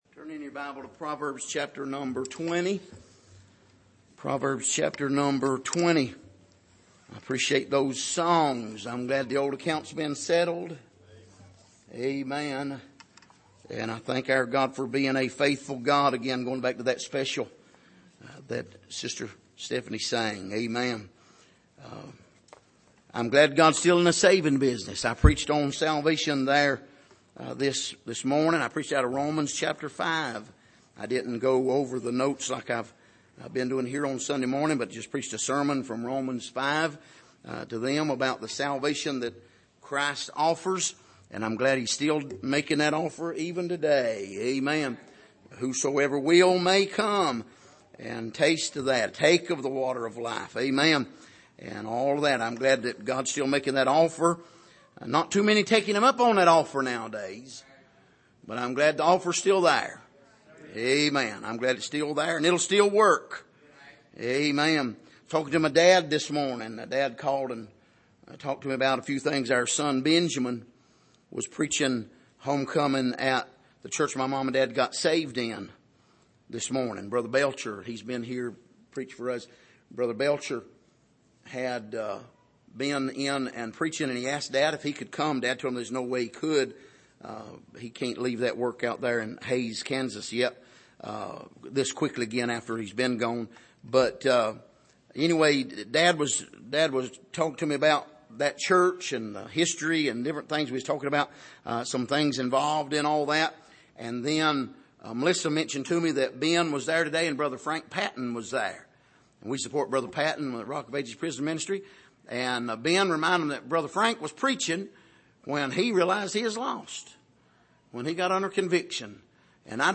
Passage: Proverbs 20:1-7 Service: Sunday Evening